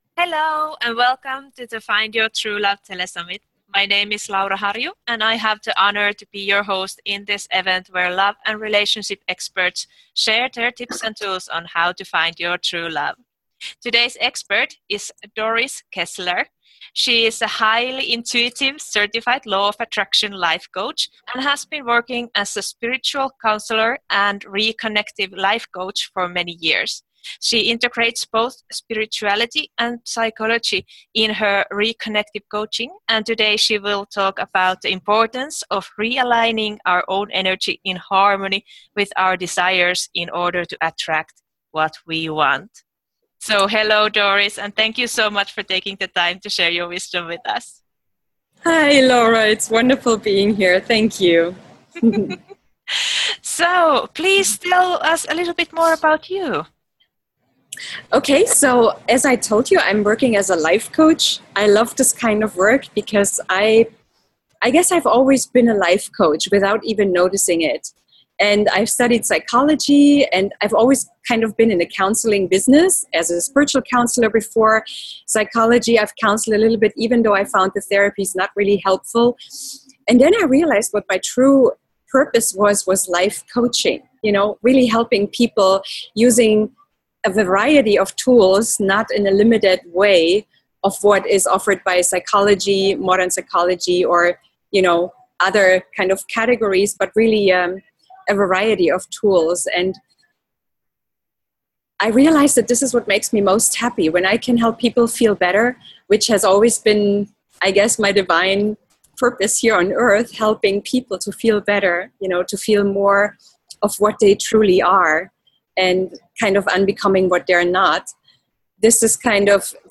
I am so happy to share this audio with you here on my private space, and if you have been struggling feeling happy in your love life, I hope this interview inspires you with some new ideas.